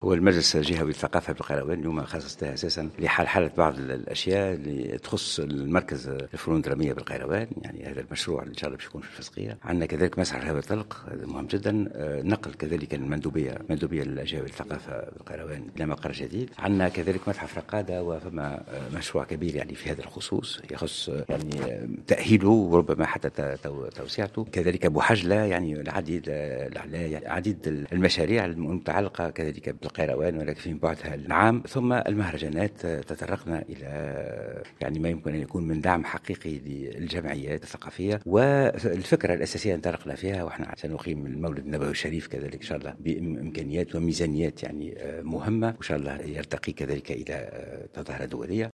وأشار في تصريح لمراسل "الجوهرة اف أم" إلى مشاريع تم النظر فيها على غرار مركز الفنون الدرامية بالقيران ومسرح الهواء الطلق ومتحف رقادة الذي سيتم تأهيله وتوسعته.